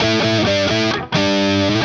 Index of /musicradar/80s-heat-samples/130bpm
AM_HeroGuitar_130-A01.wav